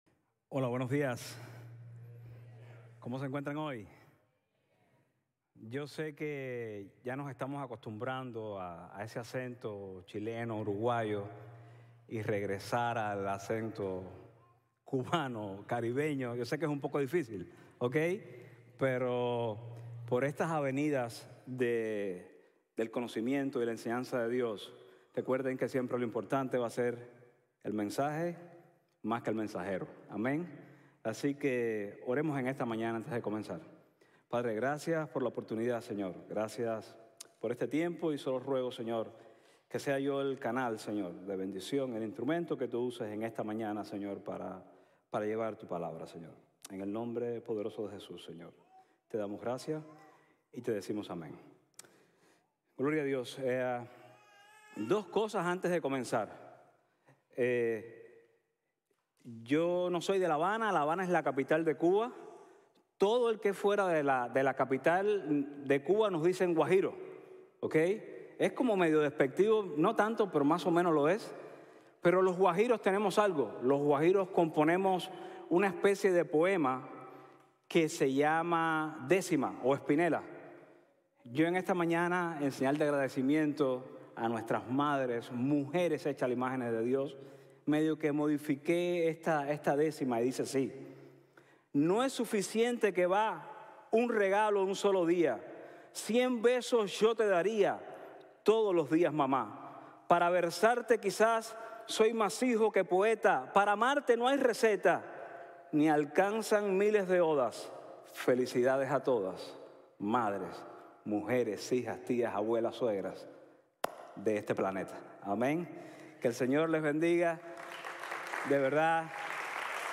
Decisión en el exilio | Sermon | Grace Bible Church